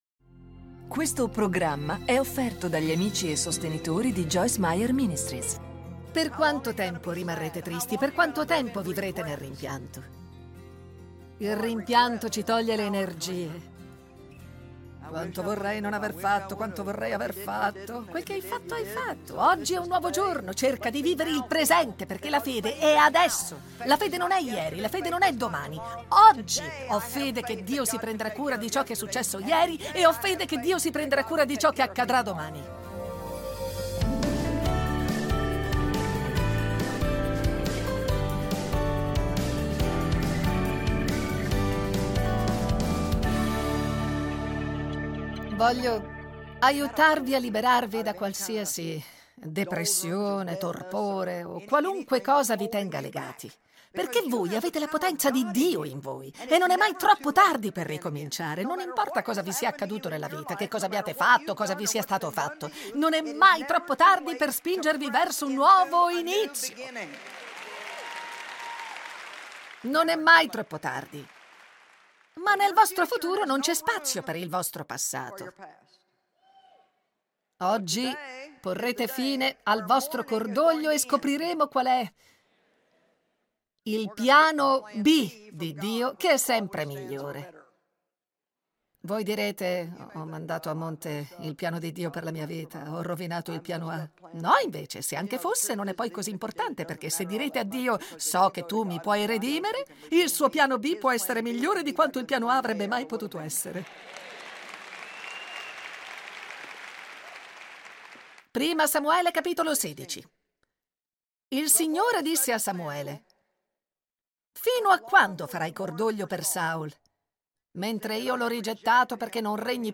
Joyce Meyer Ministries - Italiano - Predicazioni video